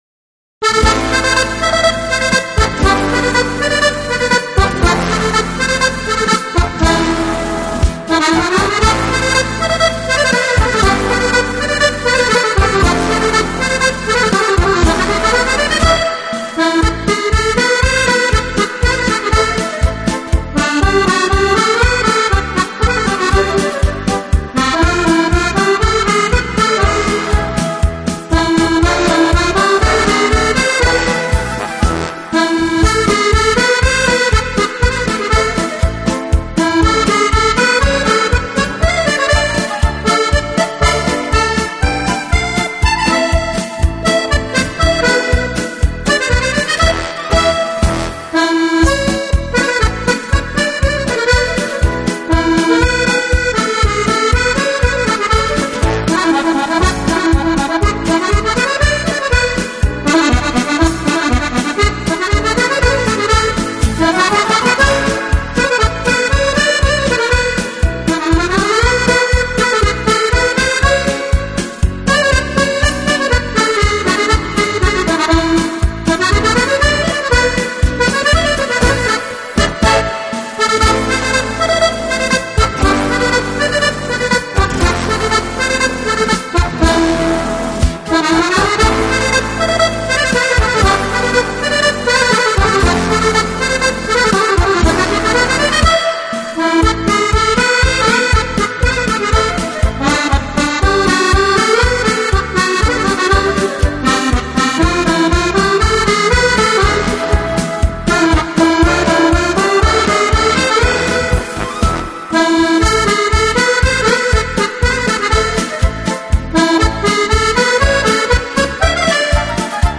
音乐类型: Pop, Instrumental Accordeon